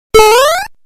Tesla Lock Sounds & Chimes Collection: Movies, Games & More - TeslaMagz
Mario Jump.wav